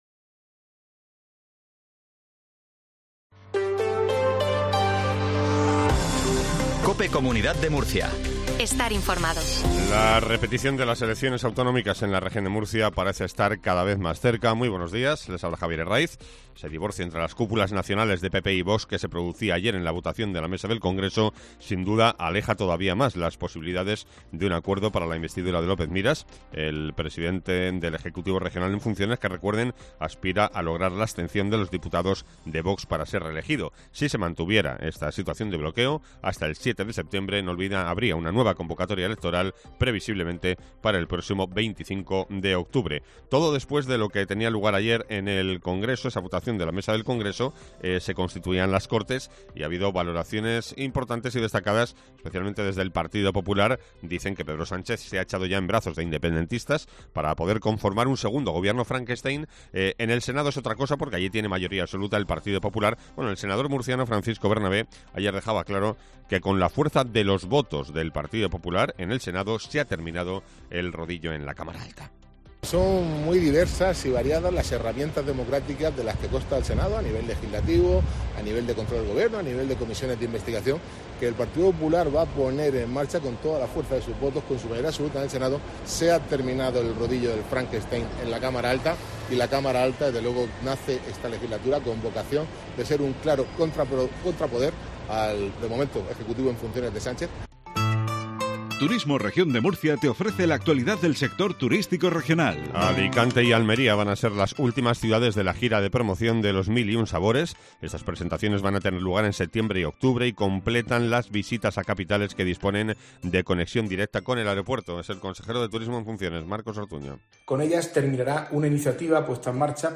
INFORMATIVO MATINAL REGION DE MURCIA 0750